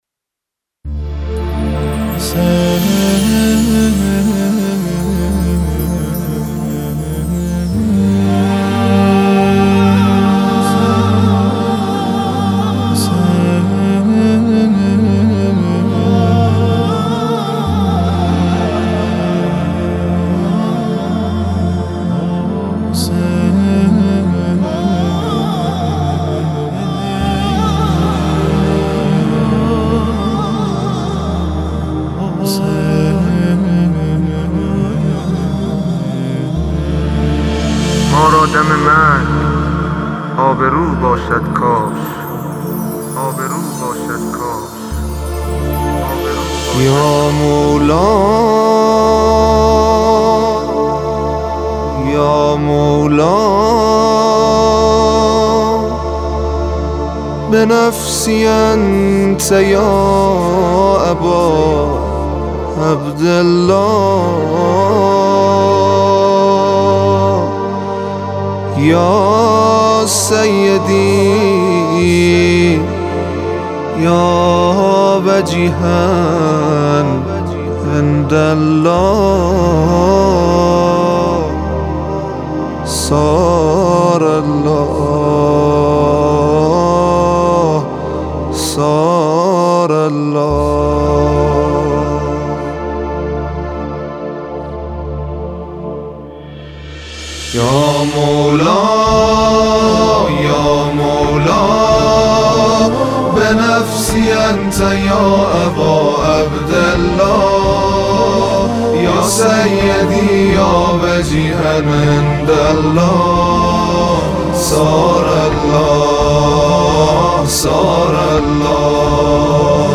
در آستانه محرم یک قطعه زمزمه عاشقانه با حال و هوای محرم اجرا کرده است.
زمزمه عاشقانه